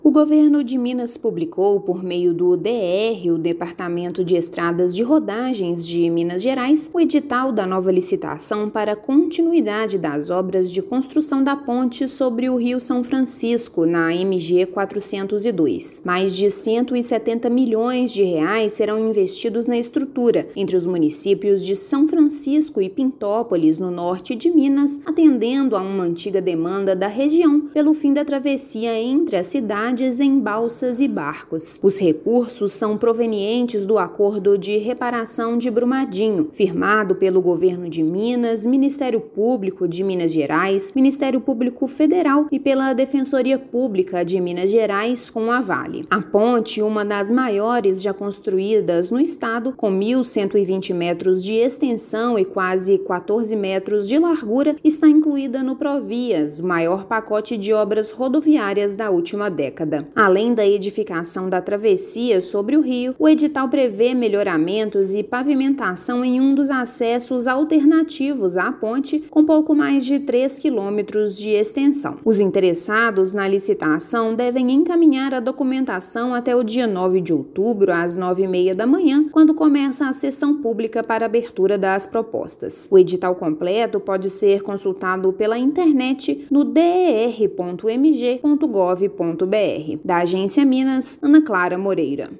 Estrutura terá mais de 1 quilômetro de extensão e vai acabar com transtorno da travessia por balsas e barcos na MG-402, no Norte do estado. Ouça matéria de rádio.